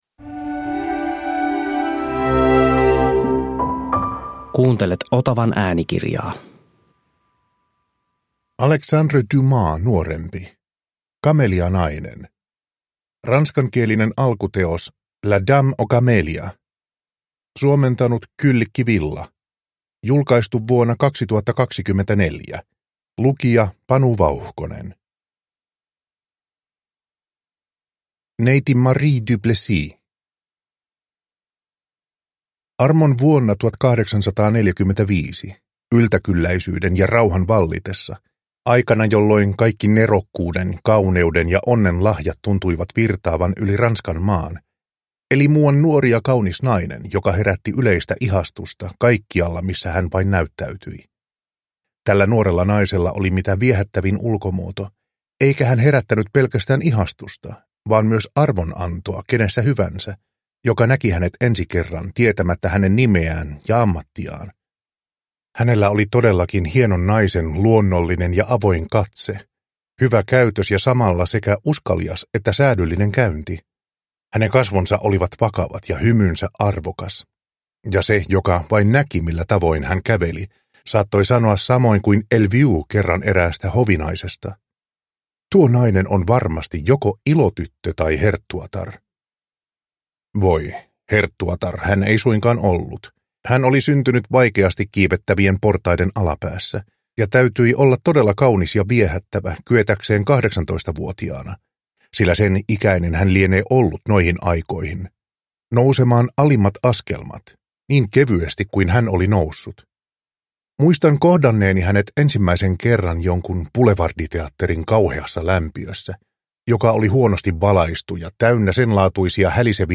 Kamelianainen – Ljudbok